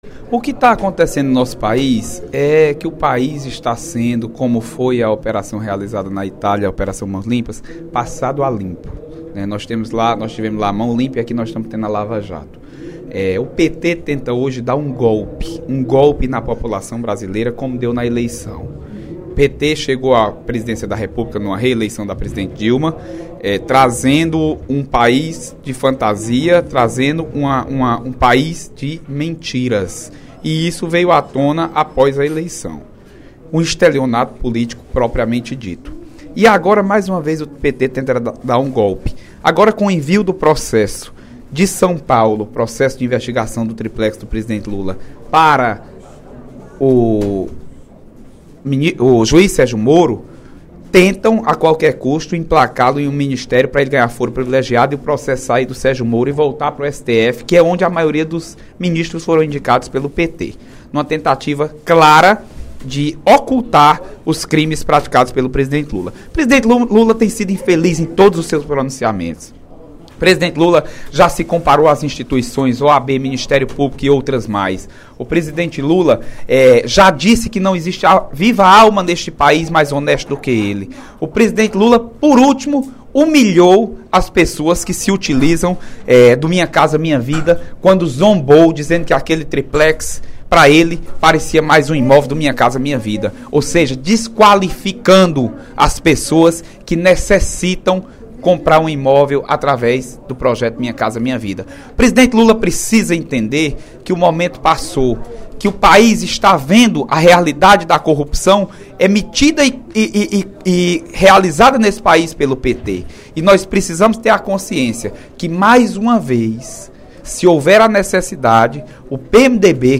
O deputado Leonardo Araújo (PMDB) avaliou, em pronunciamento no primeiro expediente da Assembleia Legislativa desta terça-feira (15/03), o momento político nacional e pediu o afastamento de Dilma Rousseff da presidência do País.